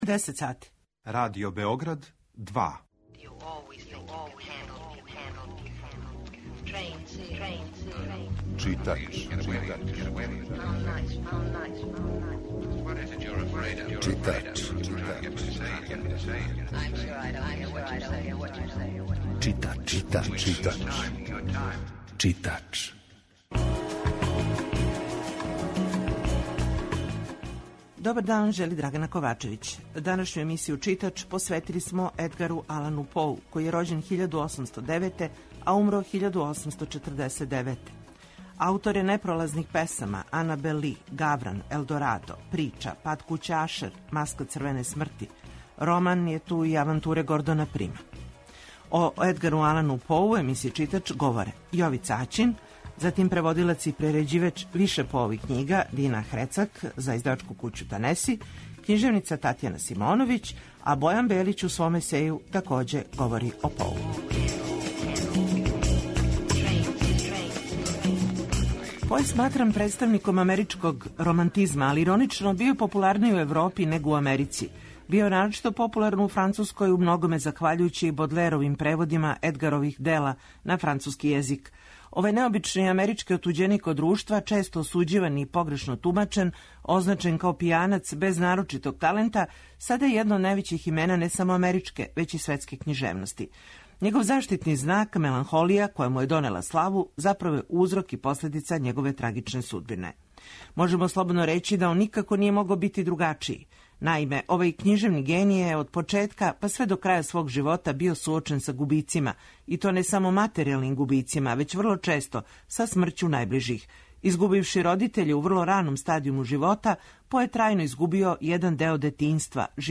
Емисија је колажног типа, али је њена основна концепција – прича о светској књижевности